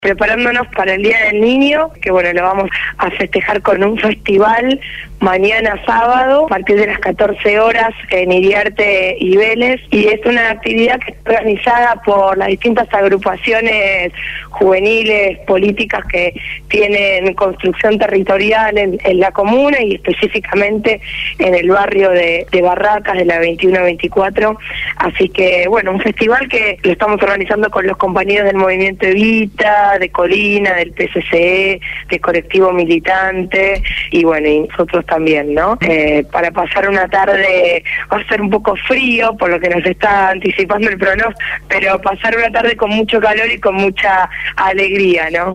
Victoria Colombo, referente de la agrupación La Cámpora y Comunera electa en la Comuna 4 por el Frente Para la Victoria habló en el programa Punto de Partida de Radio Gráfica FM 89.3 con motivo del Festival por el Día del Niño que se realizará a partir de las 14 horas en Iriarte y Vélez Sarfield, en el Barrio de Barracas de la Ciudad de Buenos Aires.